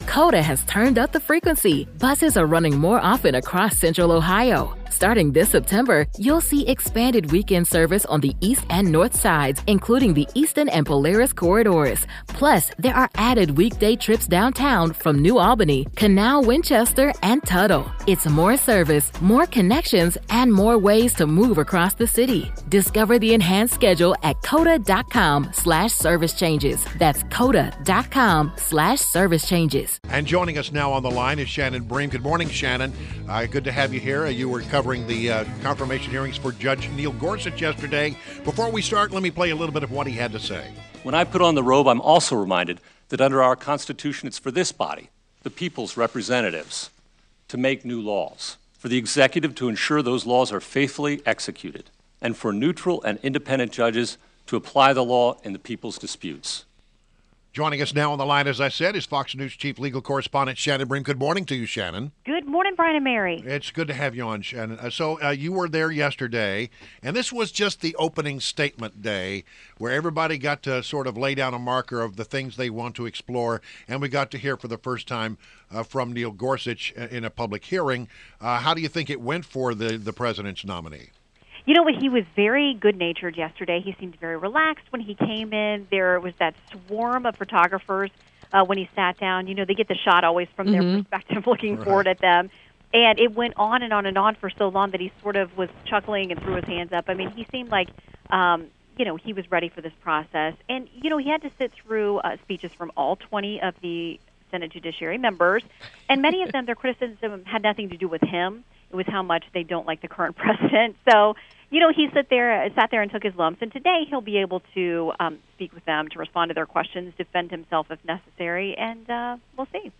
WMAL Interview - SHANNON BREAM - 03.21.17
INTERVIEW — SHANNON BREAM — Fox News Chief Legal Correspondent and has a podcast Livin the Bream on iTunes – recapped Day 1 and Preview Day 2 of the Gorsuch Supreme Court Confirmation Hearings.